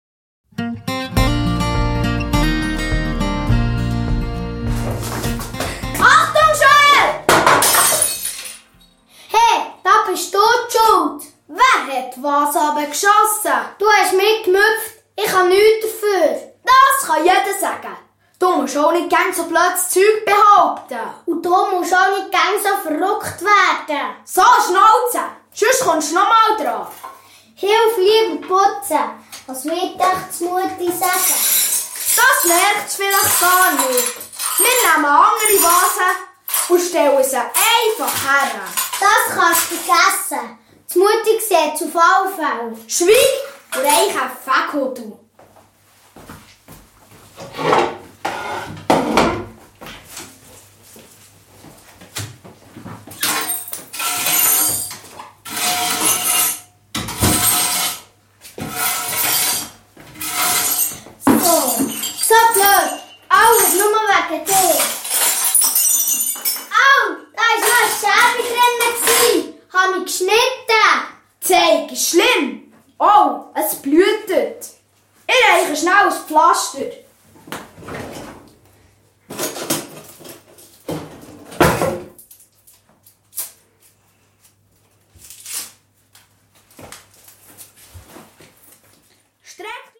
Ein turbulentes berndeutsches Hörspiel, das dazu anregt, zu seinen Missgeschicken zu stehen.
Hörspiel-Album